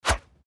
monster_atk_arrow_2.mp3